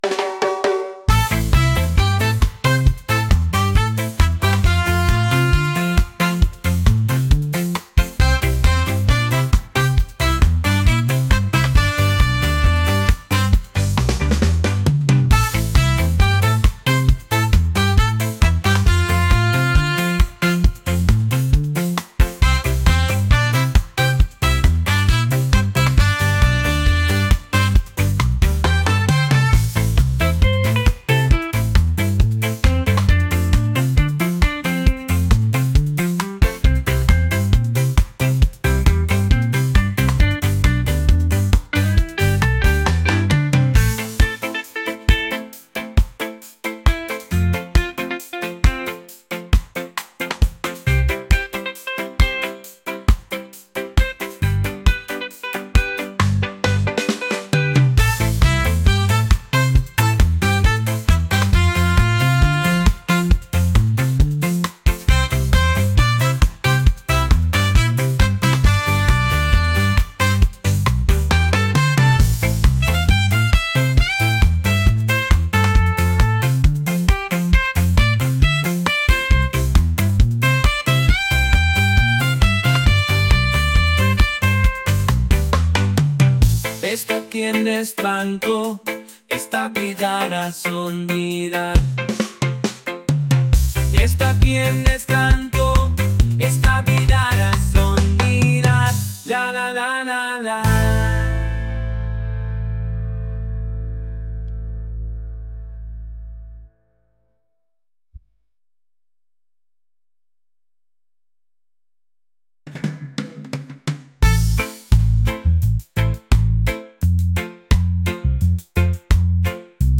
reggae | groovy | upbeat